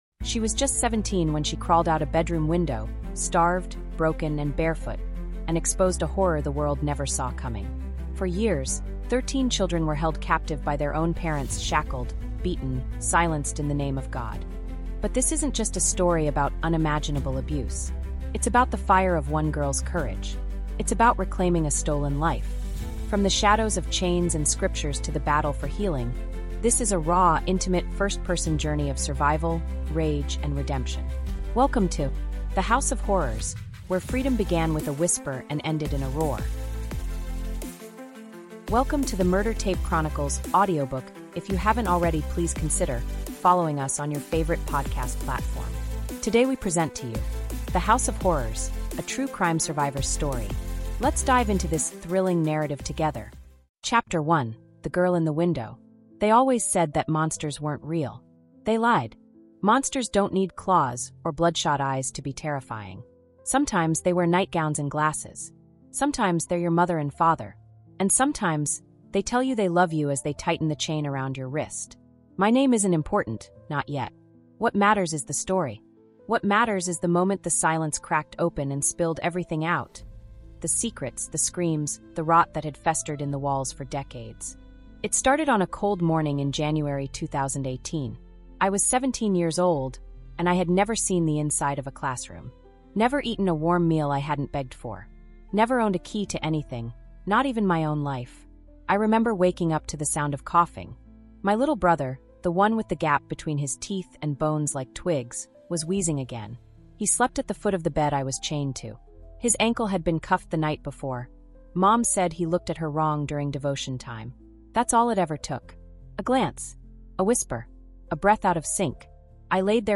Inside the walls of a quiet suburban house, thirteen siblings were being tortured, starved, and shackled by their own parents. “The House of Horrors” is a gripping five-chapter true crime audiobook told from the first-person perspective of the brave survivor who broke the silence.
From the chilling courtroom testimonies to the raw emotional recovery, experience every heartbreaking detail in this cinematic, emotionally-driven audiobook experience.